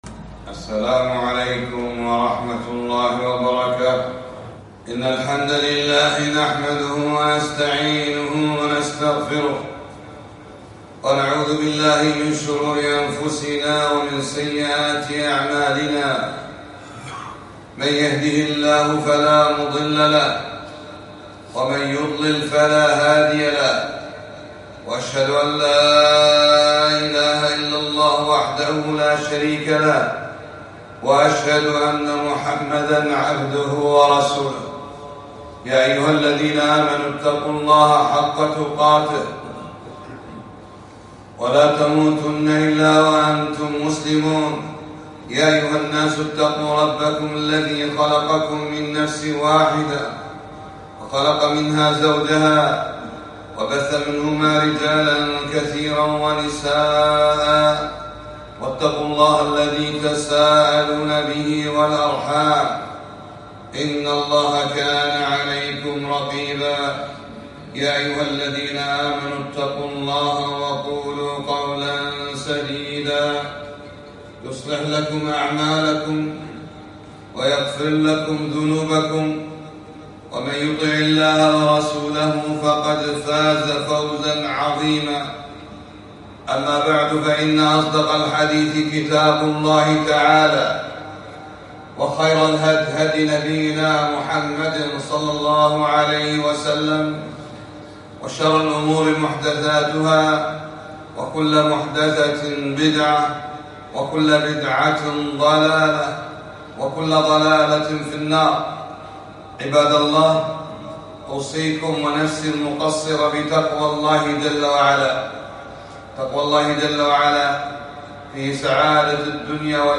خطبة - توحيد الالوهية